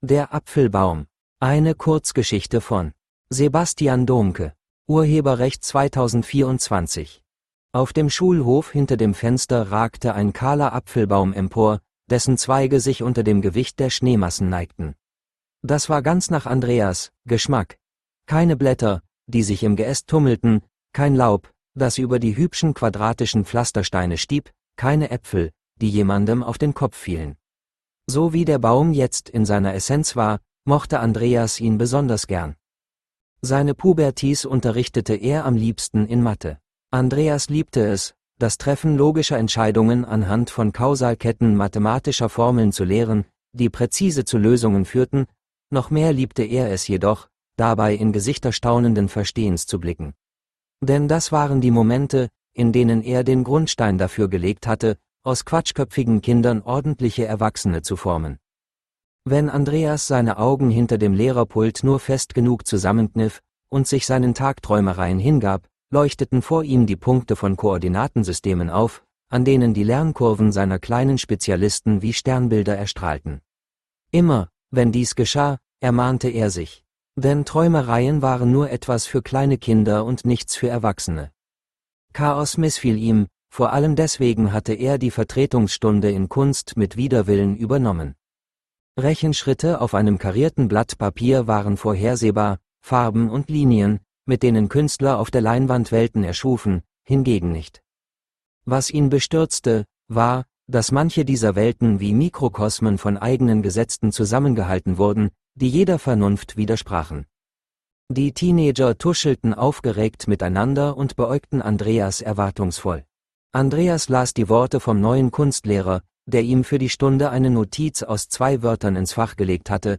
als Hörbuch vorgelesen